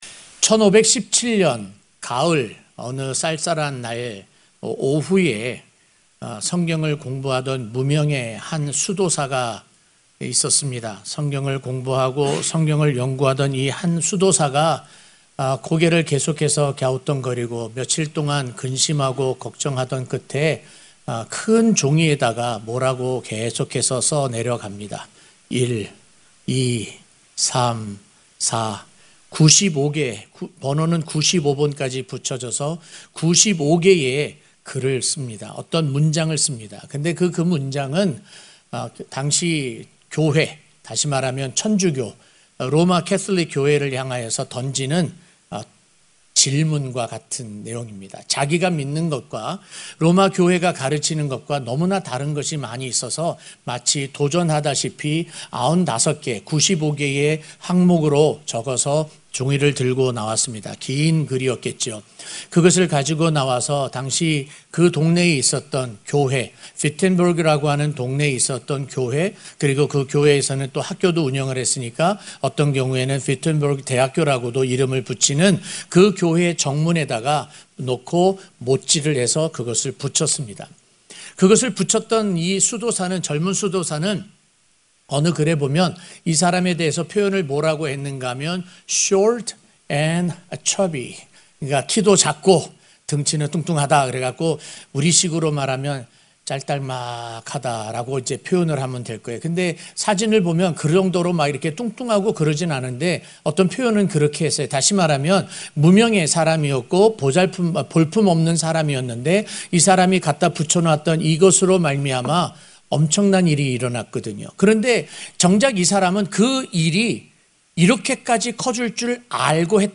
오직 성경(종교개혁기념주일 설교)